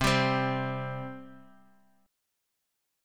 C5 chord